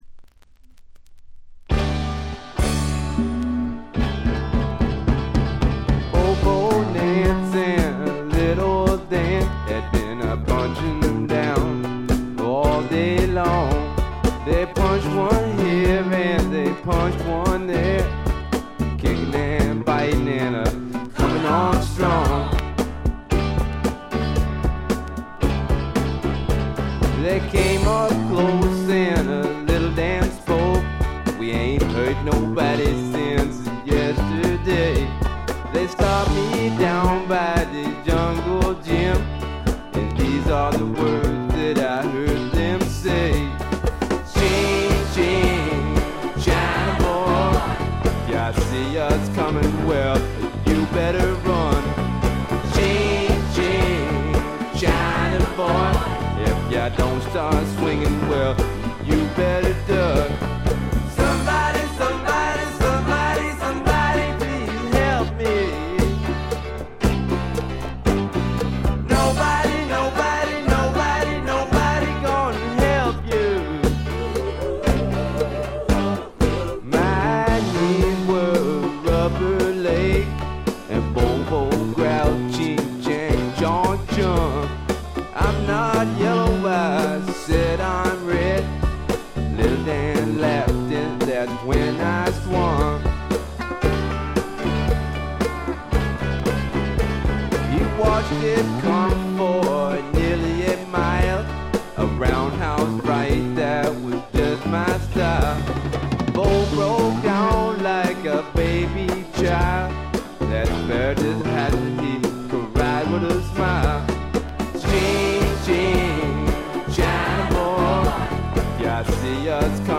A1中盤とB1序盤でプツ音1回づつ。
よりファンキーに、よりダーティーにきめていて文句無し！
試聴曲は現品からの取り込み音源です。